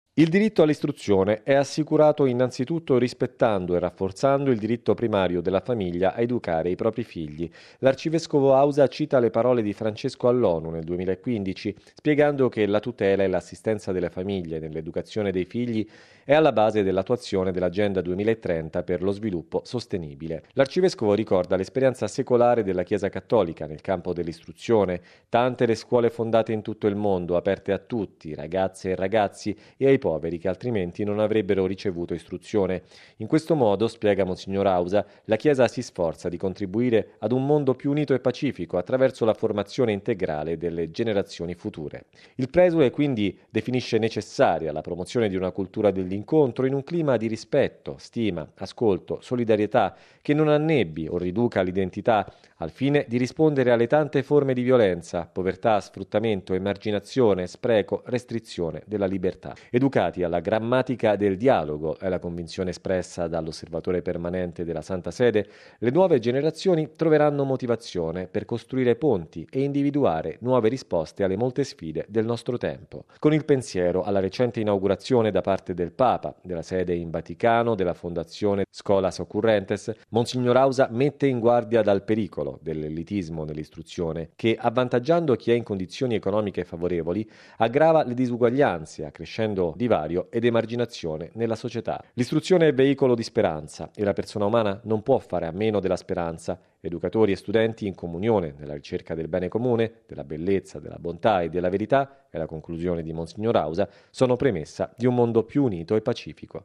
Le istituzioni educative promuovano la "grammatica del dialogo" base dell'incontro e strumento per armonizzare la diversità culturale nella ricerca della verità e trovare nuove risposte alle sfide del nostro tempo. E’ il cuore dell’intervento, pronunciato ieri all’Onu di New York, dall’Osservatore permanente della Santa Sede, mons. Bernardito Auza. Ribadita la necessità, più volte evidenziata dal Papa, di rafforzare il diritto primario delle famiglie ad educare i propri figli.